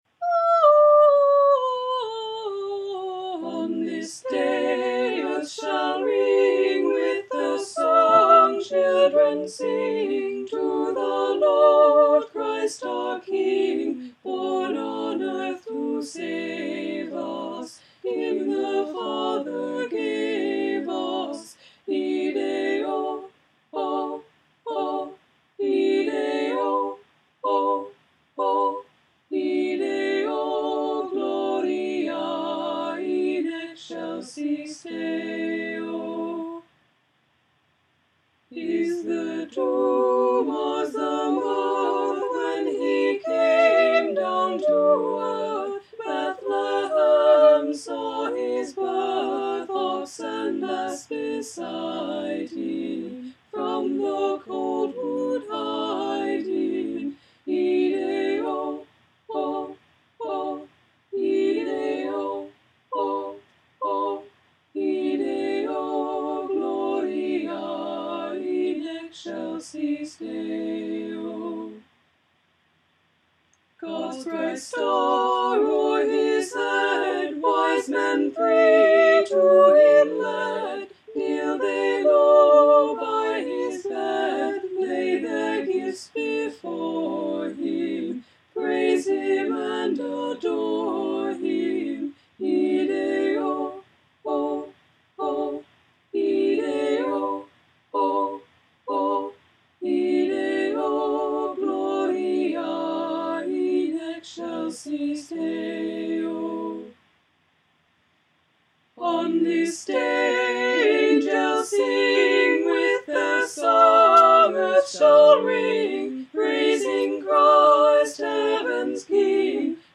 voice in four parts
Their arrangement is a vocal (SATB, sort of) version of Gustav Holst's arrangement.